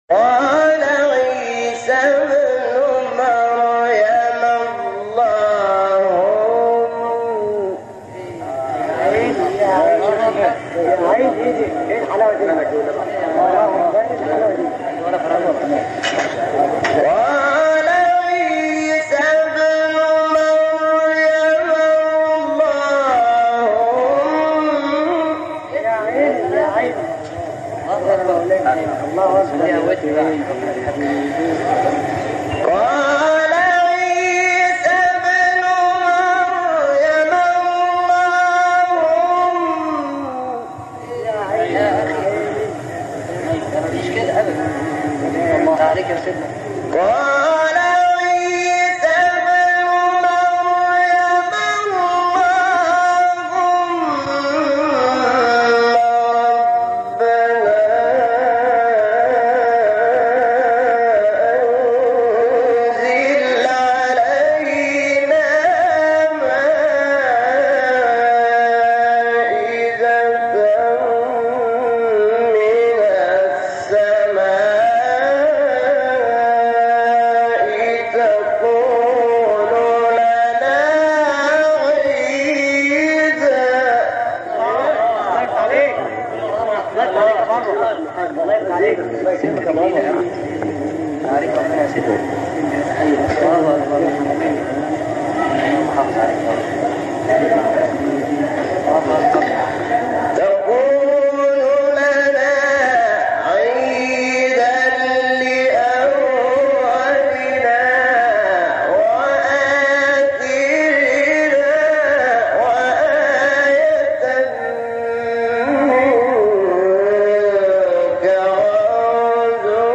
مقام : بیات